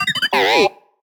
sad4.ogg